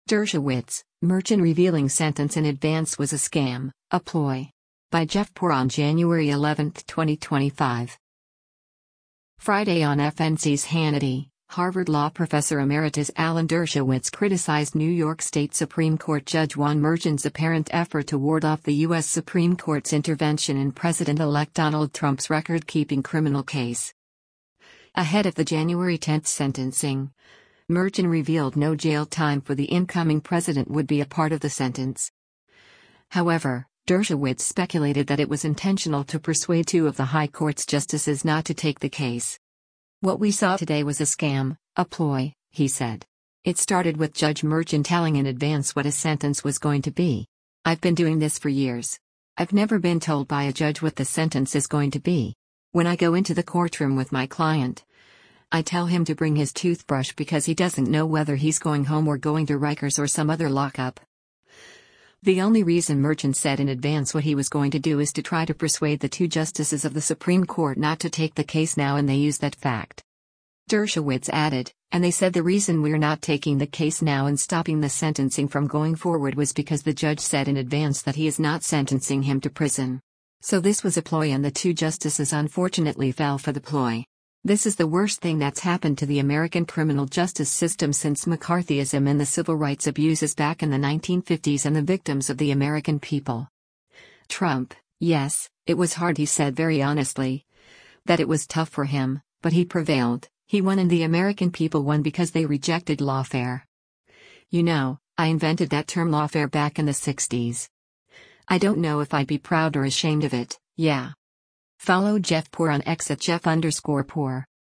Friday on FNC’s “Hannity,” Harvard Law professor emeritus Alan Dershowitz criticized New York State Supreme Court Judge Juan Merchan’s apparent effort to ward off the U.S. Supreme Court’s intervention in President-elect Donald Trump’s record-keeping criminal case.